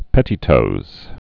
(pĕtē-tōz)